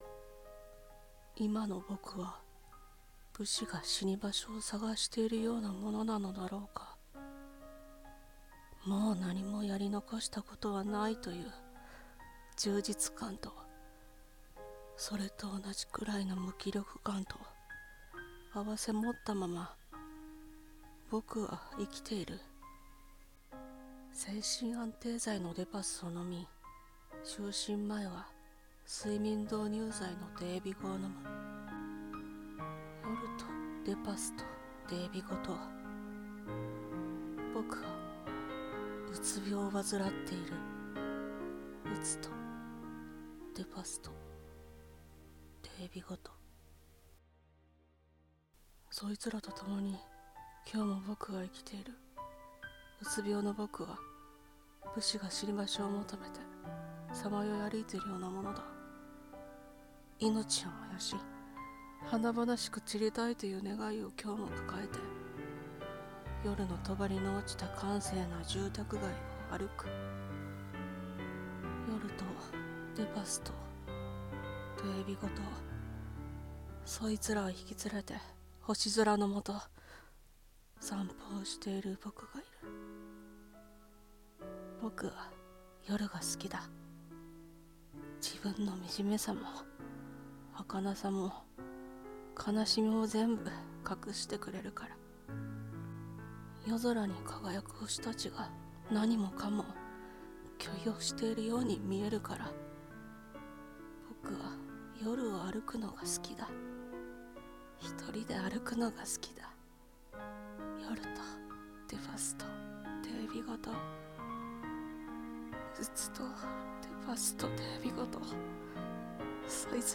夜とデパスとデエビゴと。。《朗読》